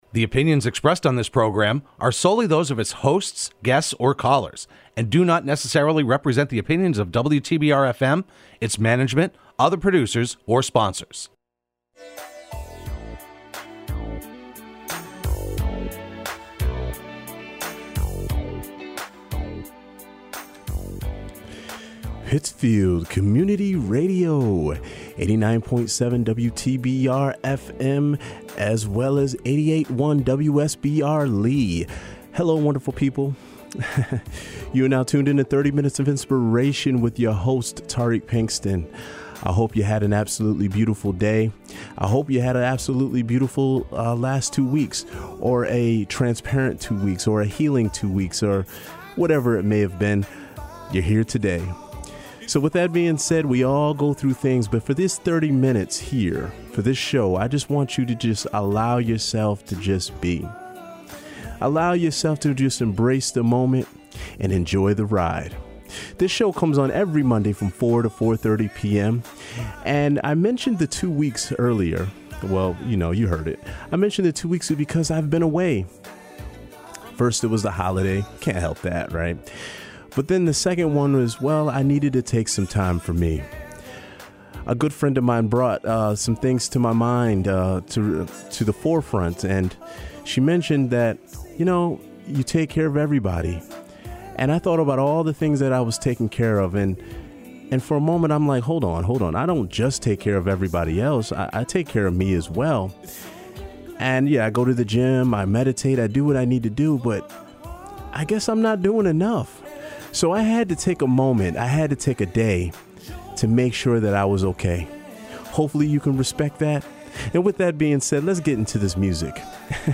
broadcast live every Monday afternoon at 4pm on WTBR